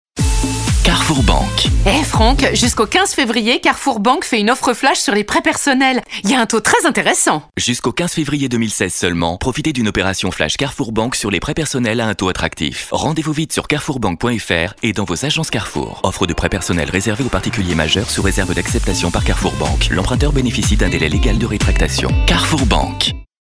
Sa diction irréprochable, sa rigueur technique et sa capacité à garder une intention malgré la contrainte de temps font toute la différence.
2. CARREFOUR BANQUE droit, rapide